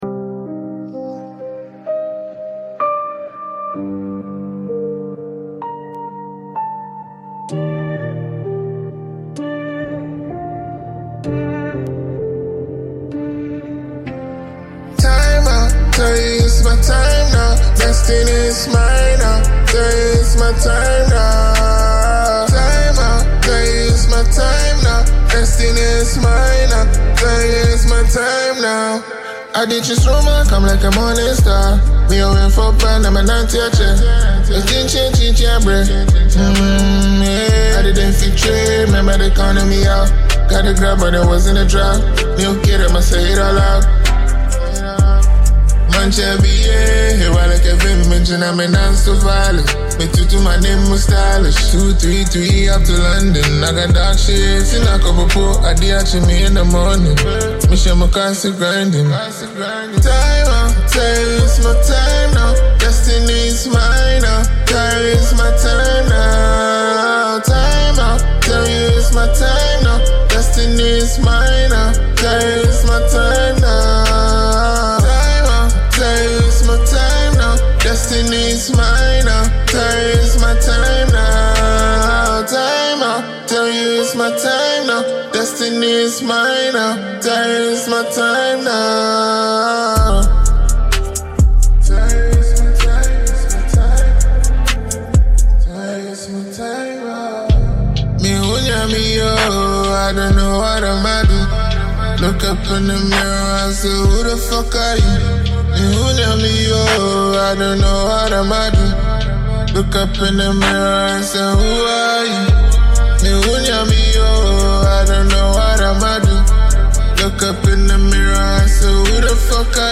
a smooth and reflective vibe
blending mellow melodies with introspective lyrics.
Genre: Afro-Fusion